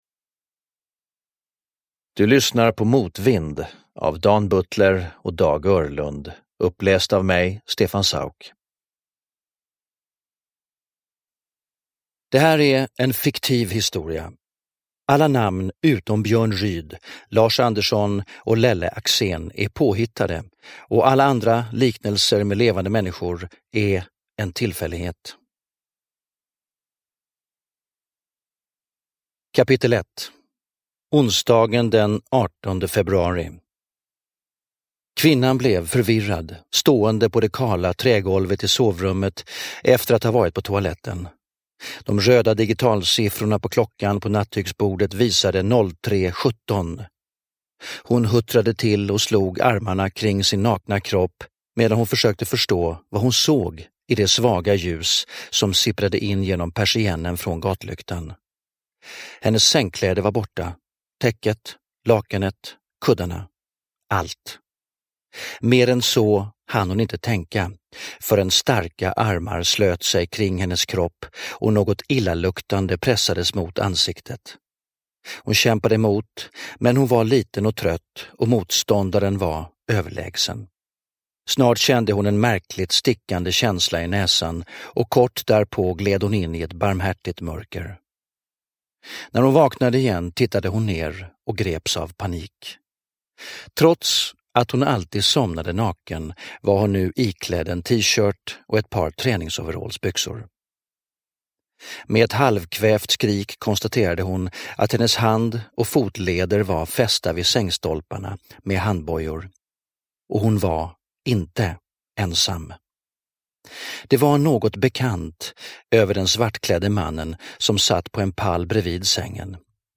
Uppläsare: Stefan Sauk
Ljudbok